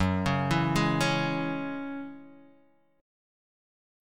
F#7sus2 chord